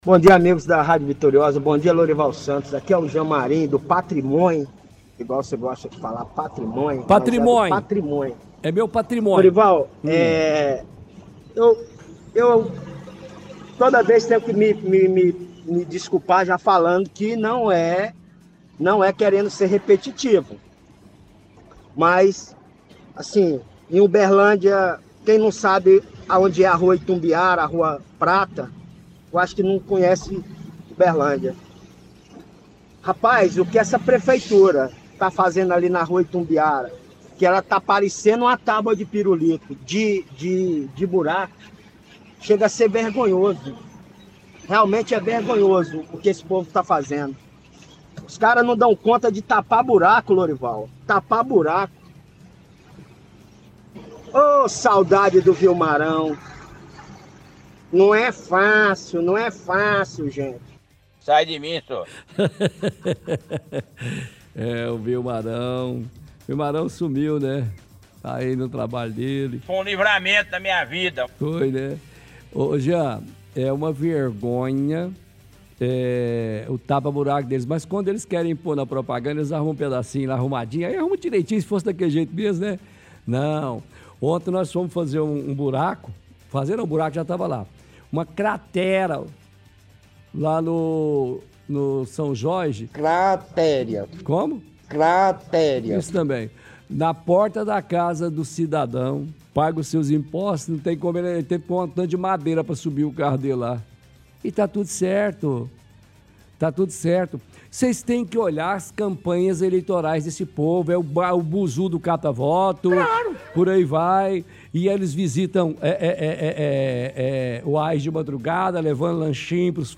– Ouvinte reclama de buracos na av. Itumbiara.